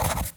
menu-play-click.ogg